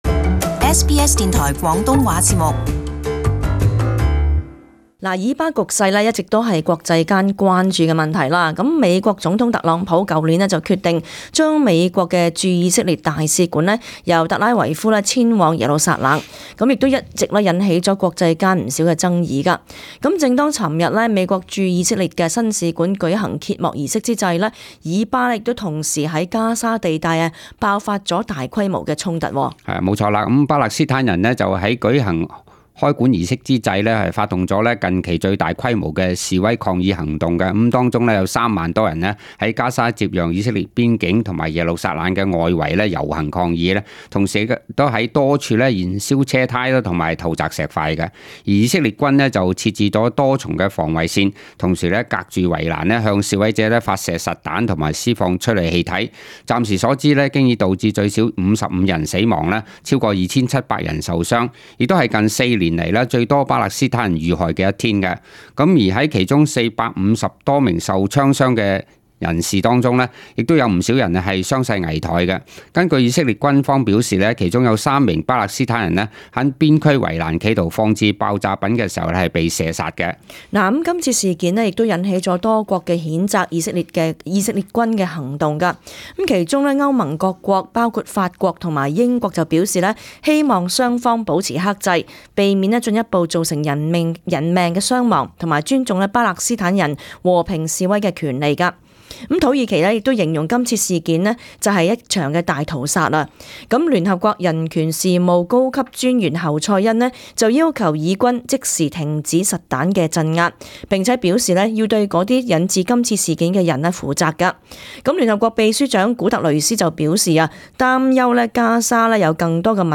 【時事報導】巴勒斯坦大規模示威，超過50 人被殺。